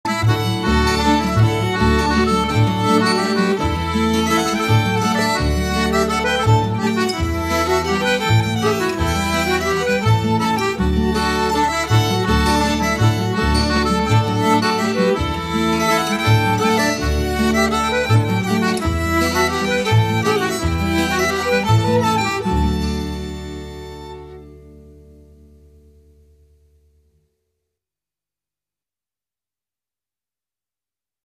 valse impaire (3 et 5 temps) 2'53